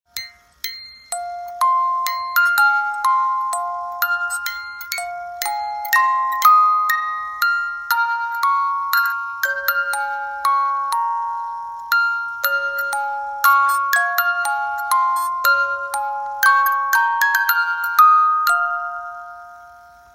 Cover , Rock